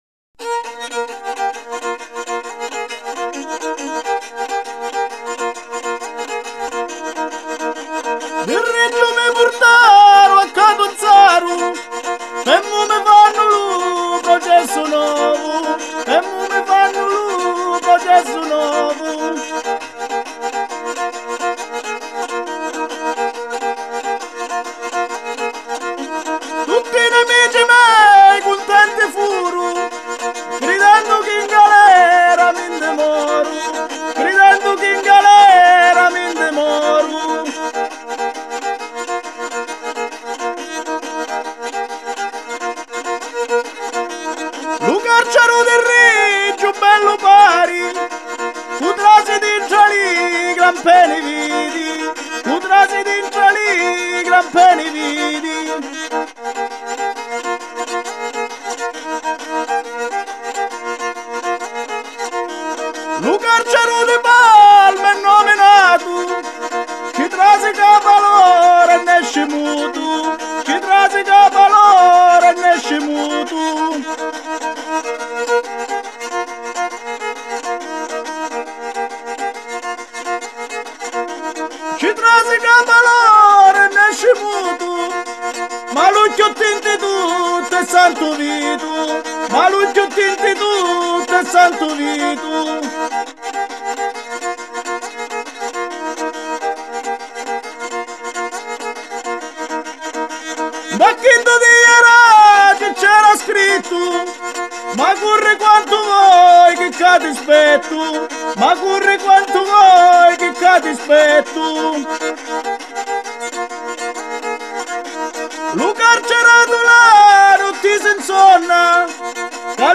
Жанр: Mafia Folk